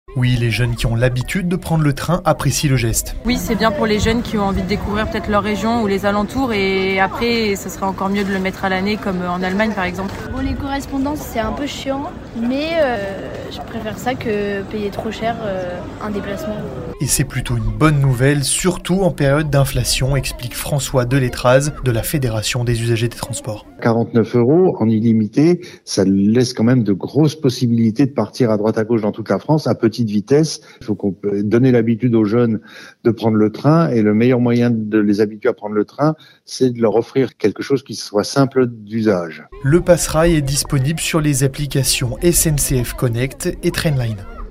Une mesure qui semble avoir trouvé son public avec un prix attractif, comme en témoignent ces usagers